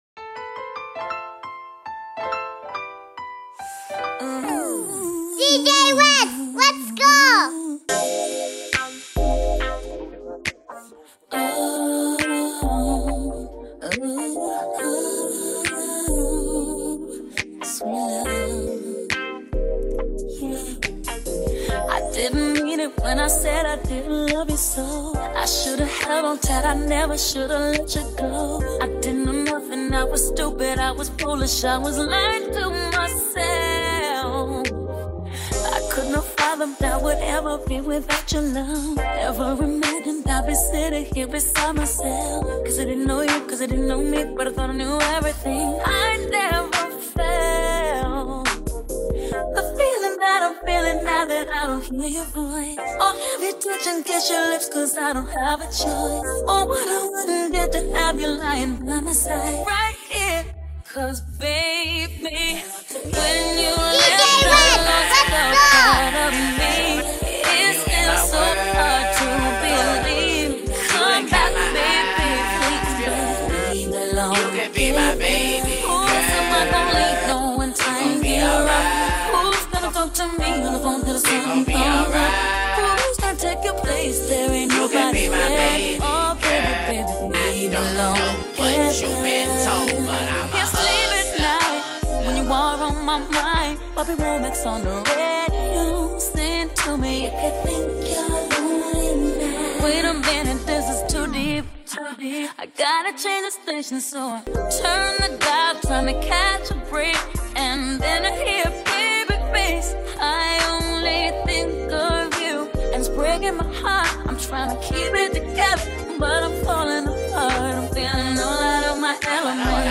HipHop
RnB